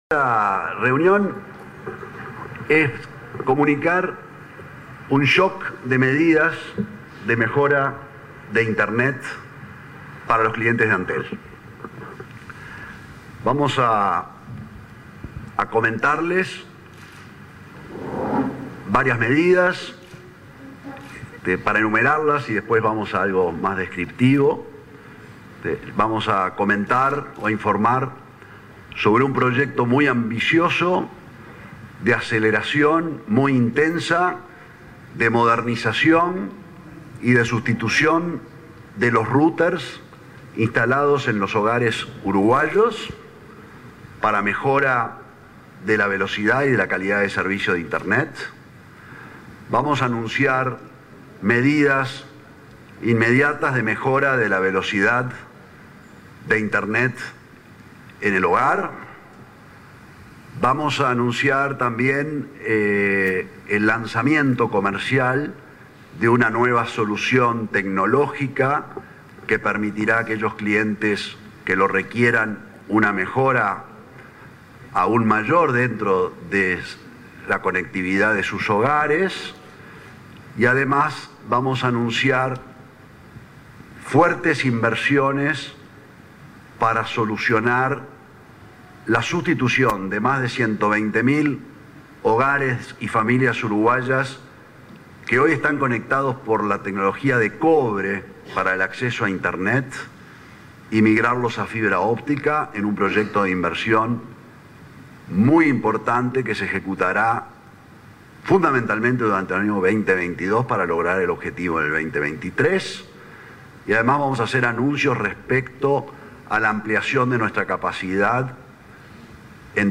Conferencia de prensa del presidente de Antel, Gabriel Gurméndez
El titular del ente efectuó una conferencia de prensa para informar del tema.